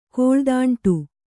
♪ kōḷdāṇṭu